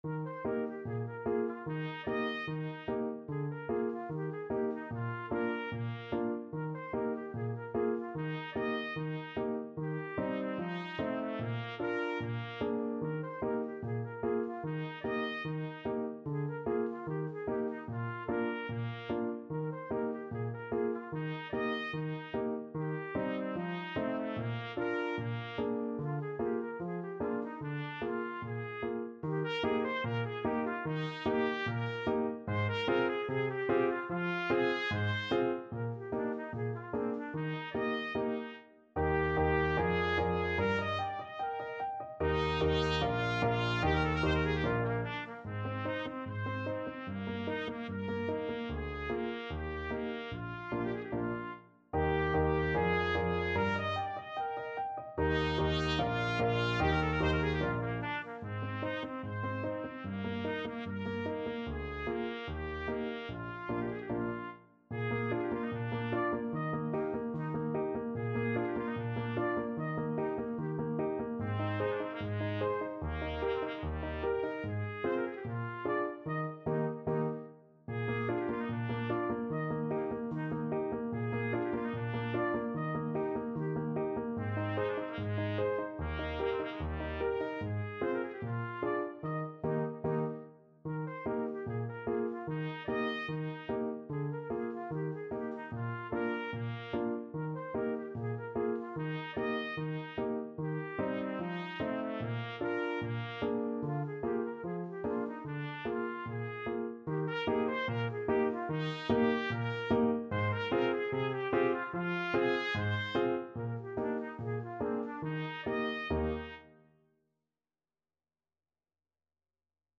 Trumpet
Eb major (Sounding Pitch) F major (Trumpet in Bb) (View more Eb major Music for Trumpet )
Allegretto = 74
2/2 (View more 2/2 Music)
Classical (View more Classical Trumpet Music)